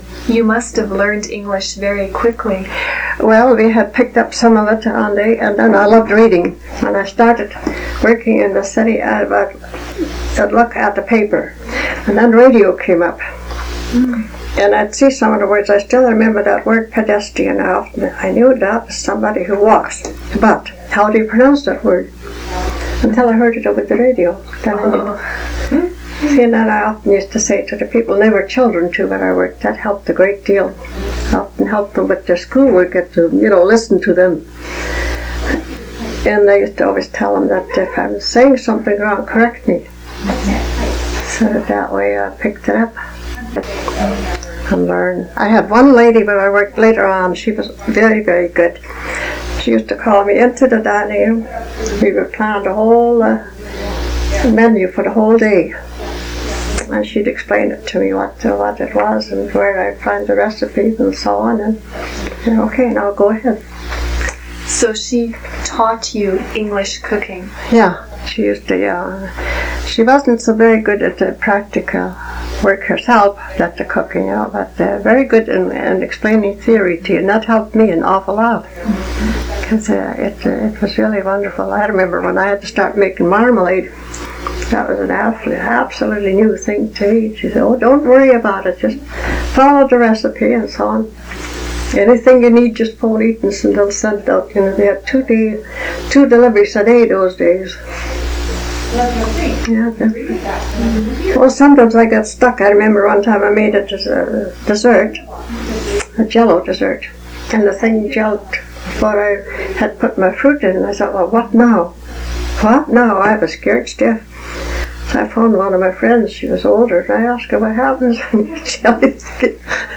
It involved the digitization of audio recordings from analogue cassette tapes to digital mp3 files.